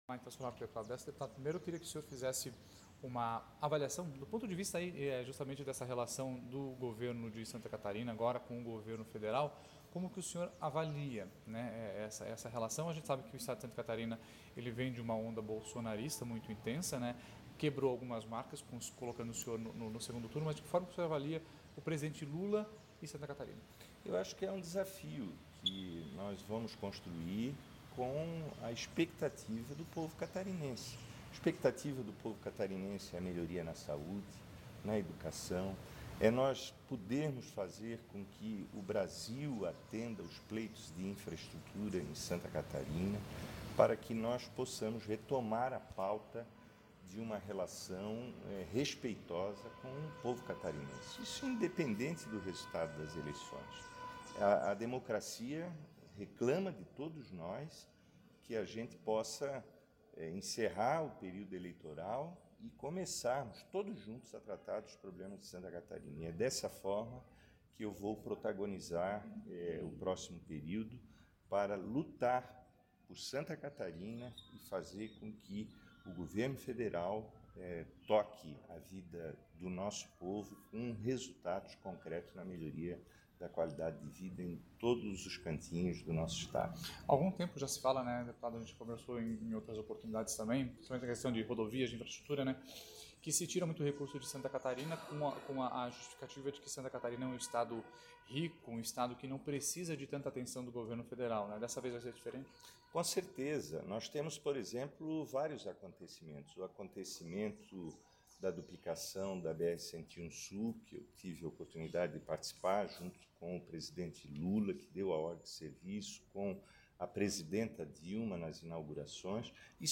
Ele falou com a reportagem do Grupo ND e, na ocasião, assim como sua correligionária, mencionou a volta do “respeito” e da “honestidade” para o país. Confira abaixo a entrevista completa do petista.